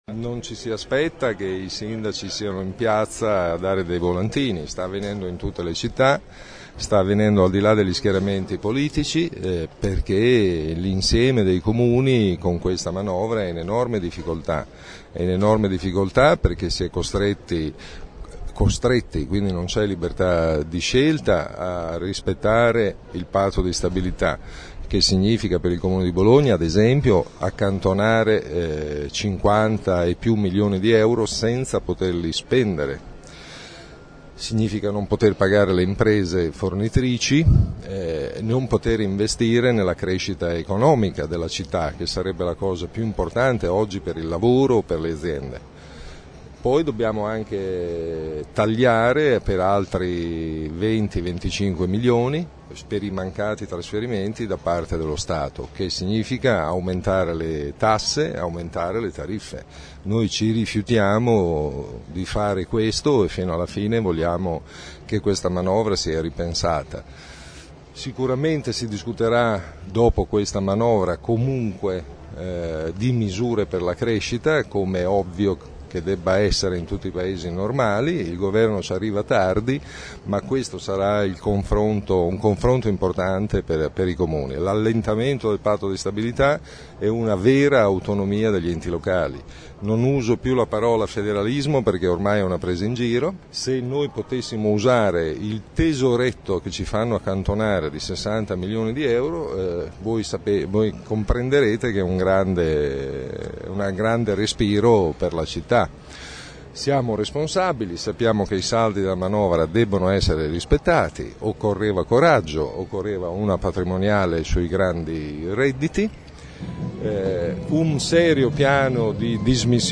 Il sindaco Merola ha volantinato per una ventina di minuti di fronte all’Urp del Comune in Piazza Maggiore.
Ascolta Merola